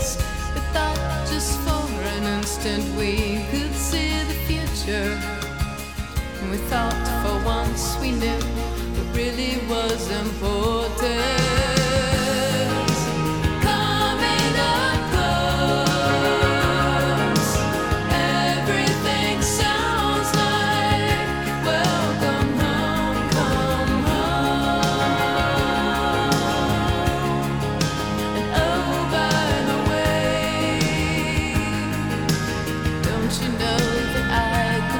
Жанр: Поп музыка / Рок / Альтернатива
Rock, Alternative, College Rock, Pop